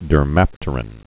(dər-măptər-ən)